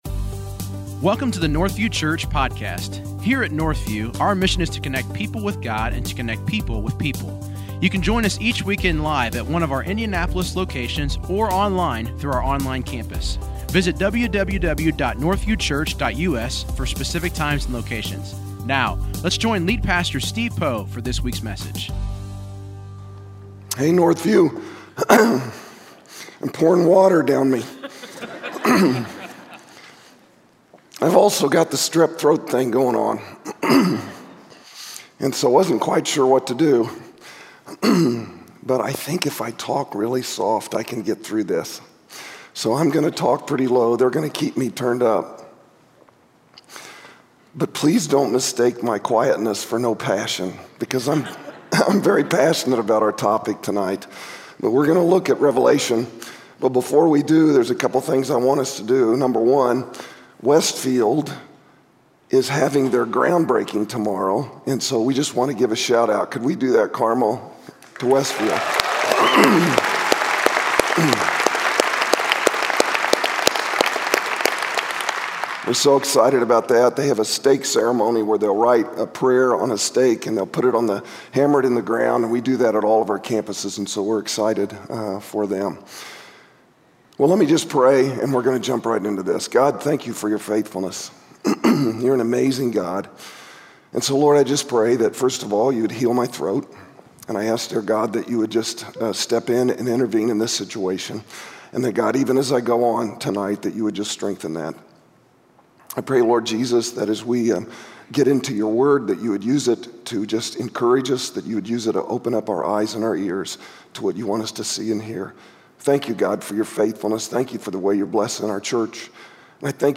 Christ will come suddenly and unexpectedly. In this message we learn about the Second Coming of Christ